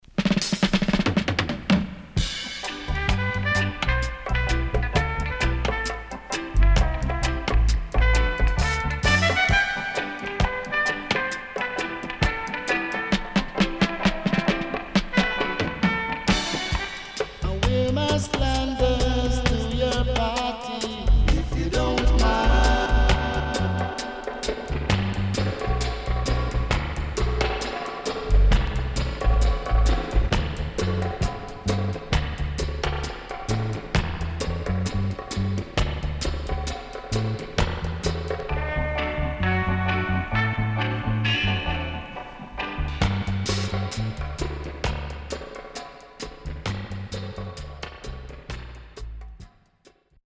HOME > Back Order [VINTAGE 7inch]
いなたい良曲 & Dubwise.W-Side Good
SIDE A:少しチリノイズ入りますが良好です。